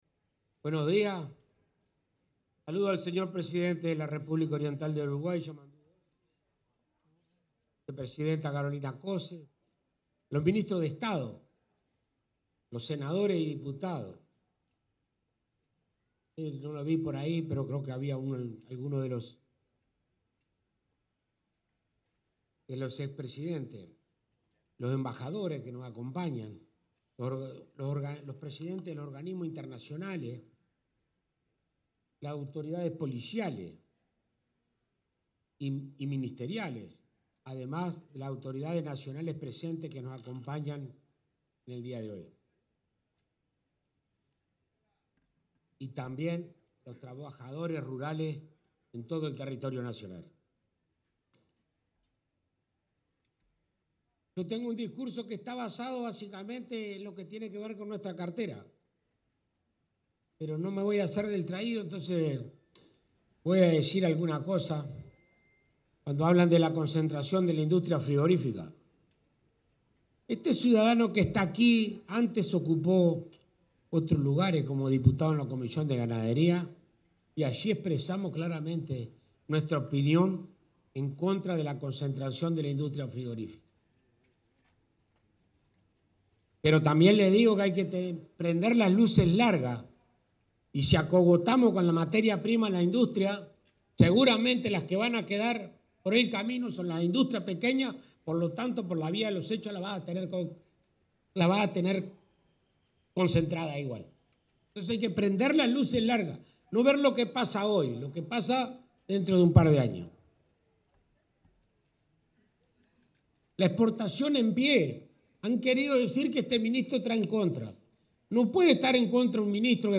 El ministro de Ganadería, Agricultura y Pesca, Alfredo Fratti, fue el orador en representación del Poder Ejecutivo en el acto de clausura de la Expo